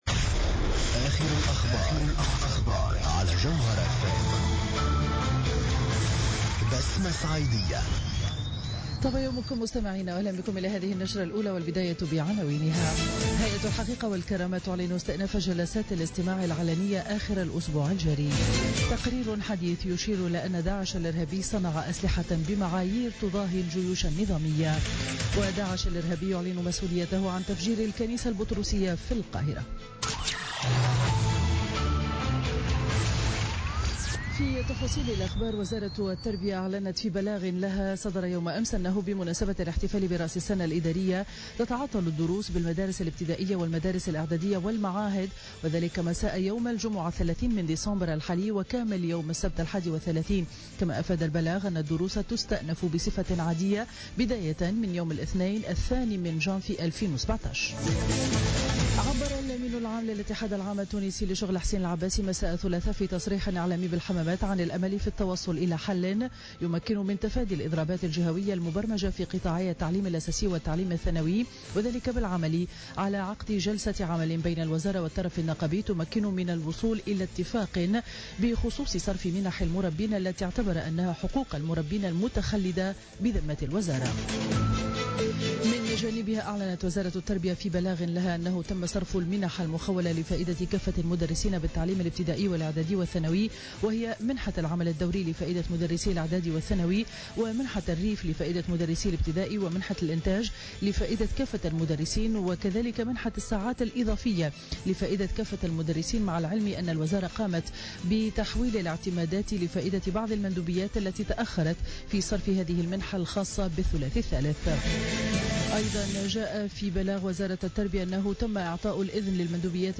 نشرة أخبار السابعة صباحا ليوم الأربعاء 14 ديسمبر 2016